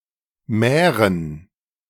Moravia[a] (Czech: Morava [ˈmorava] ⓘ; German: Mähren [ˈmɛːʁən] ⓘ) is a historical region in the east of the Czech Republic and one of three historical Czech lands, with Bohemia and Czech Silesia.
De-Mähren2.ogg.mp3